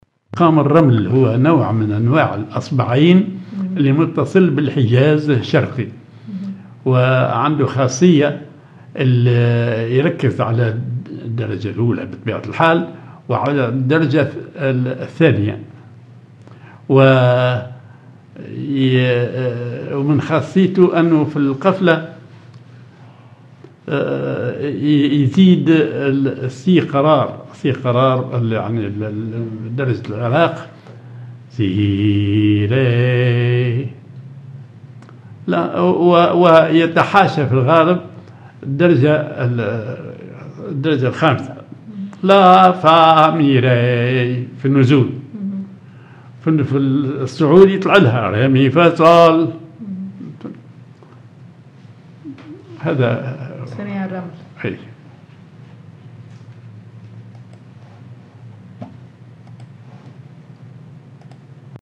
Maqam ar الرمل
Rhythm ID سماعي ثقيل
genre سماعي